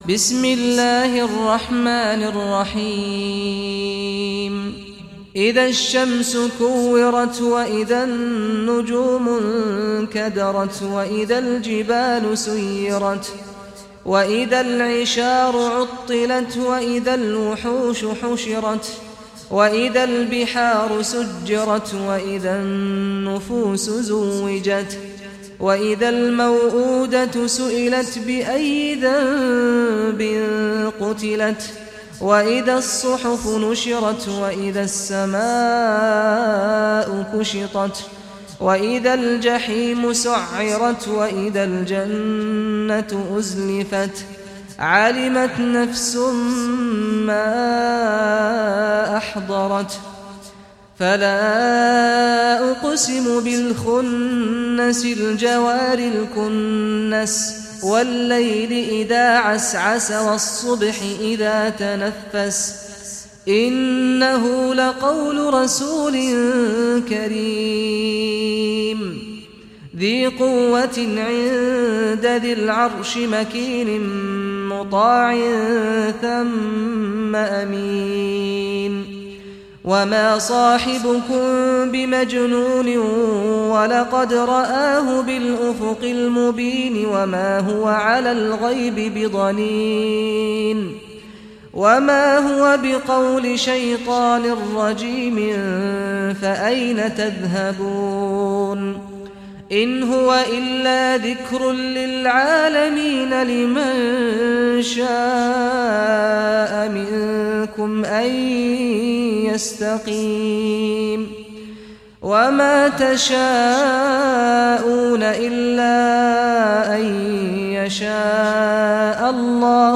Surah At-Takwir Recitation by Sheikh Saad Ghamdi
Surah At-Takwir, listen or play online mp3 tilawat / recitation in Arabic in the beautiful voice of Sheikh Saad al Ghamdi.